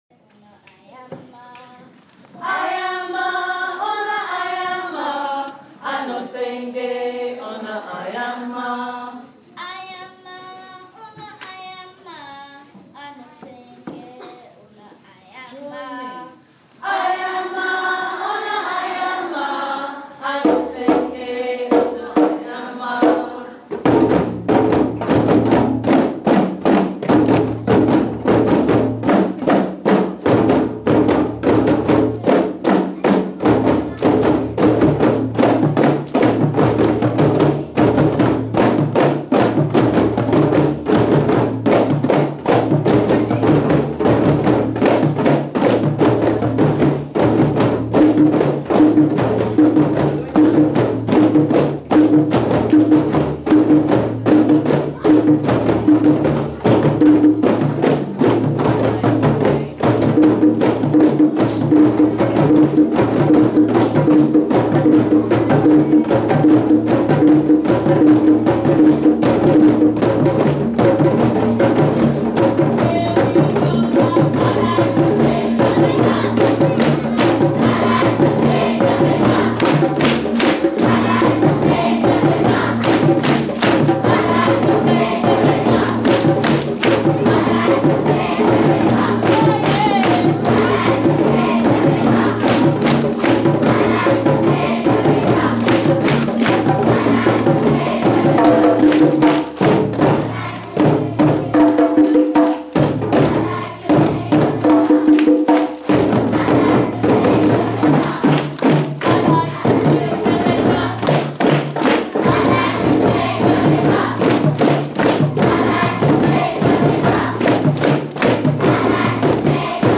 Year 6 Drumming Concert